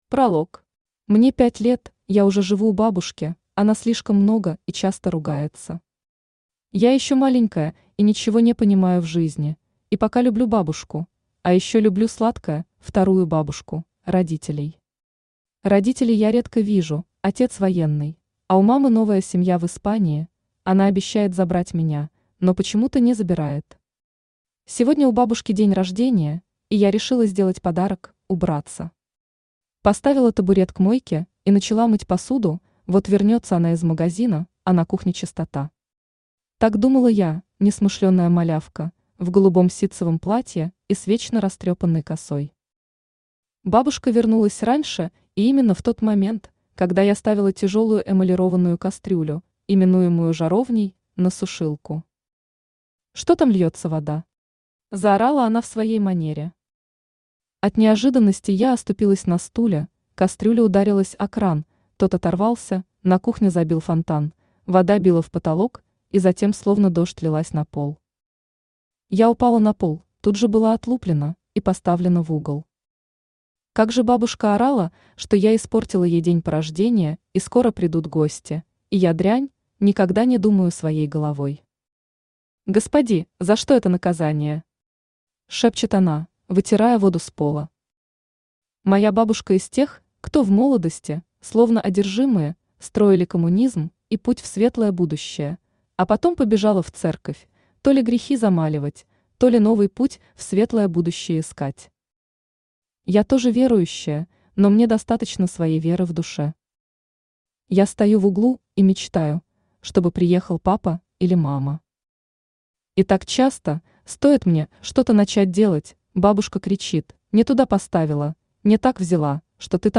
Аудиокнига Еще один повод сказать тебе нет | Библиотека аудиокниг
Aудиокнига Еще один повод сказать тебе нет Автор Ляна Сени Читает аудиокнигу Авточтец ЛитРес.